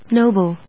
音節no・ble 発音記号・読み方
/nóʊbl(米国英語), nˈəʊbl(英国英語)/